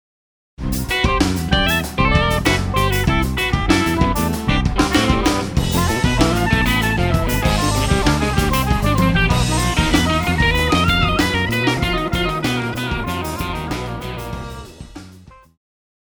爵士
旋律和絃譜,電吉他,中音薩克斯風
樂團
演奏曲
爵士搖滾,時尚爵士,現代爵士,融合爵士
獨奏與伴奏,五重奏
有節拍器